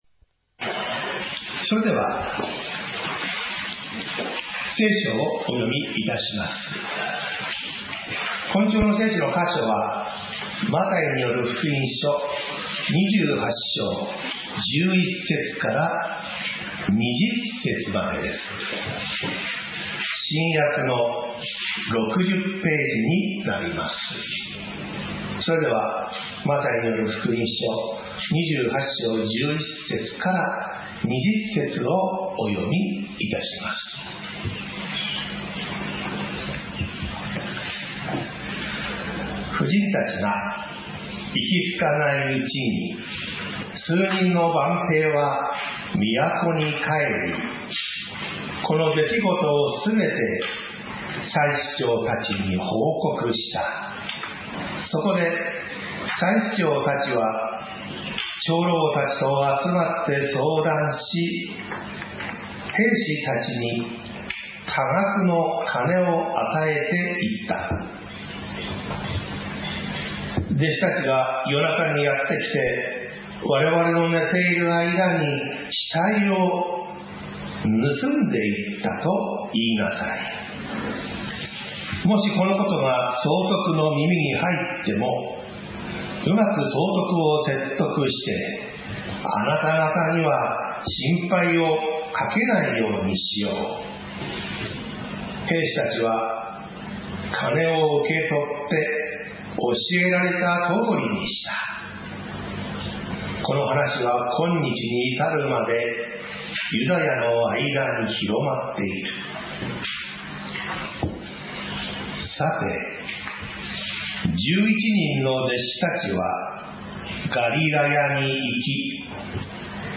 私たちは毎週日曜日10時30分から11時45分まで、神様に祈りと感謝をささげる礼拝を開いています。
礼拝説教アーカイブ